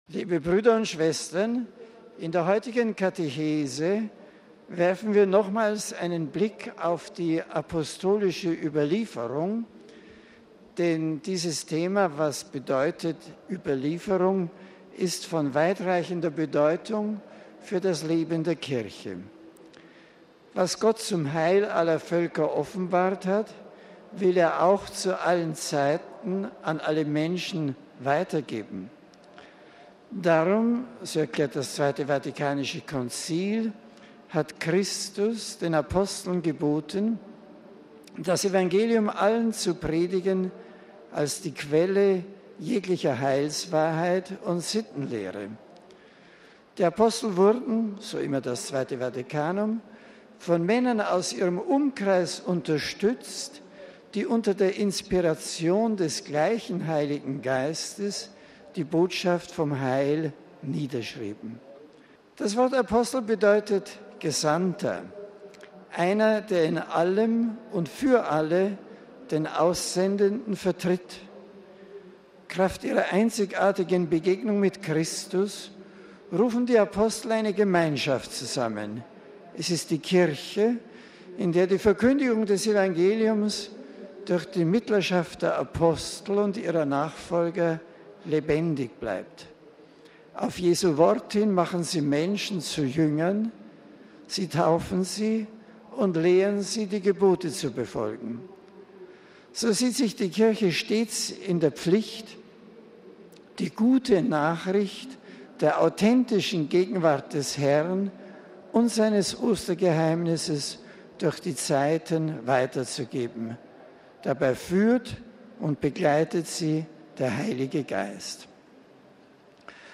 Vatikan: Generalaudienz. "Gebt gute Nachricht weiter"
MP3 60.000 Menschen haben heute auf dem Petersplatz bei schönem Wetter an der Generalaudienz des Papstes teilgenommen. Dabei begrüßte Benedikt XVI. auch viele Besucher aus seiner Heimat.